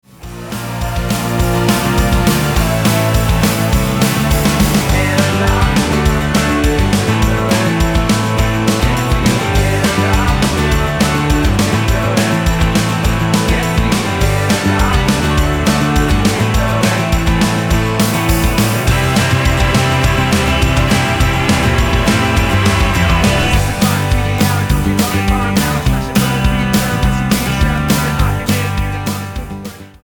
--> MP3 Demo abspielen...
Tonart:G mit Chor